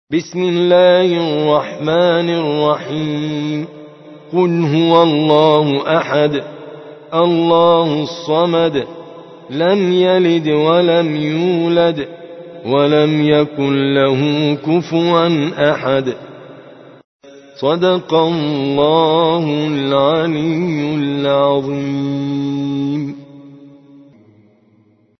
سورة الإخلاص / القارئ